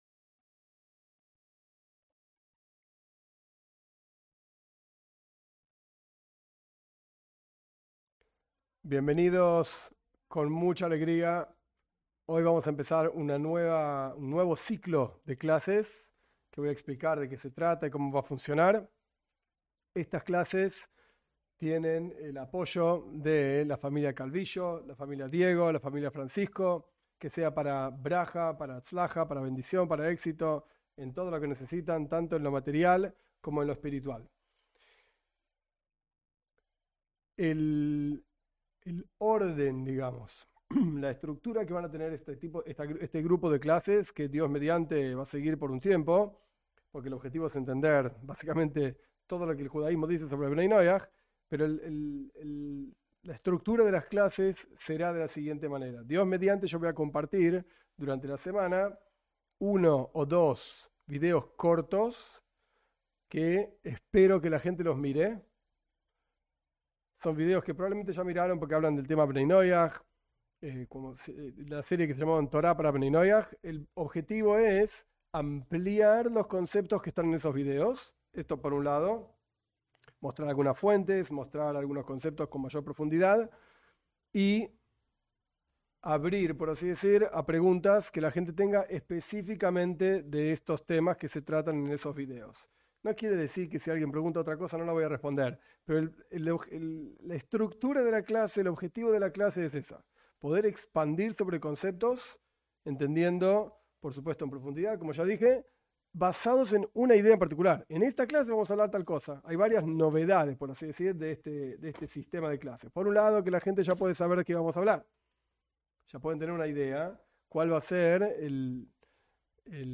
Esta es una serie de clases basada en unos videos cortos sobre asuntos de Bnei Noaj. en cada clase se analiza en detalle los puntos clave de esos videos cortos.
Cada clase tiene un tiempo de preguntas que los participantes preparan de antemano.